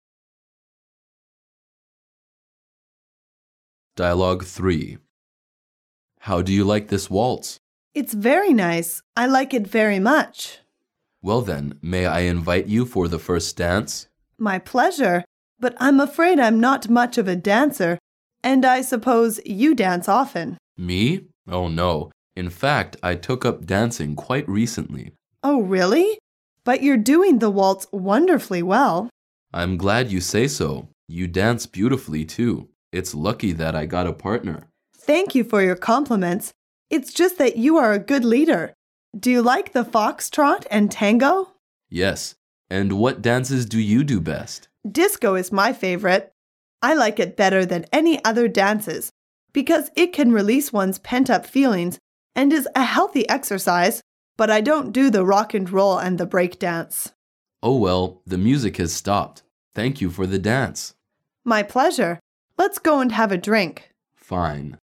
Dialoug 3